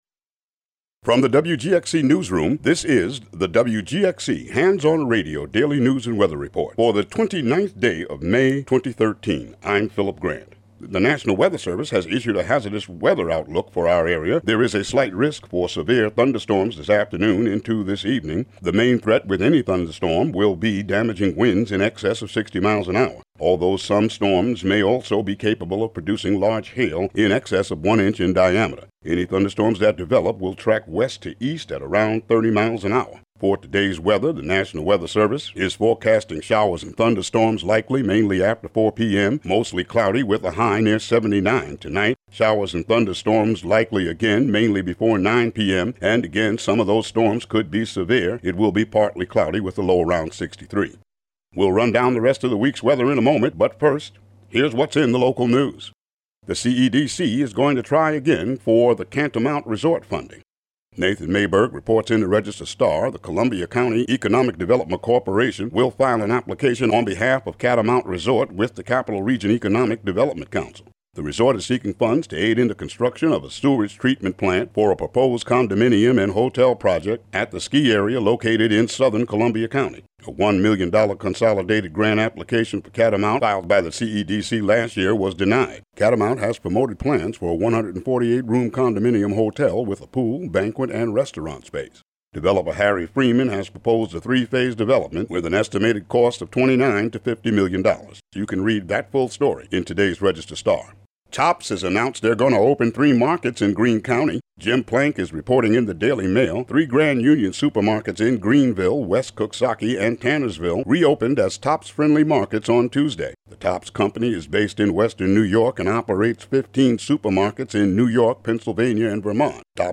Local news and weather for Wednesday, May 29, 2013.